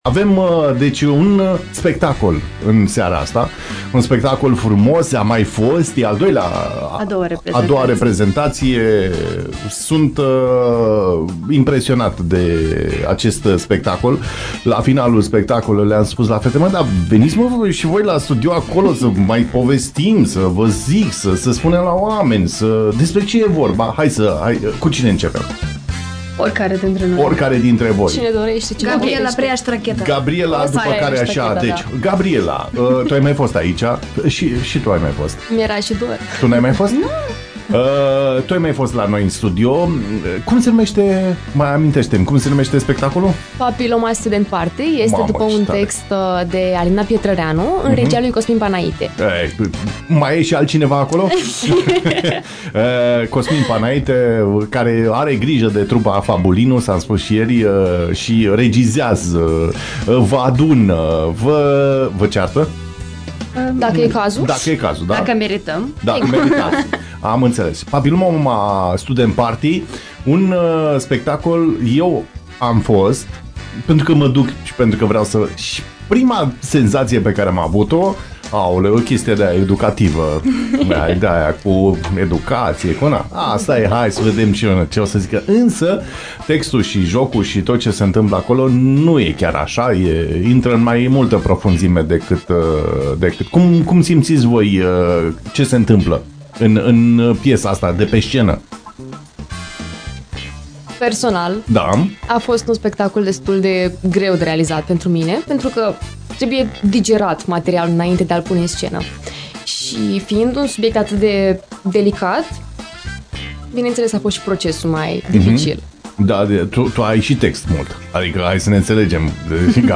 Despre acest proiect au vorbit live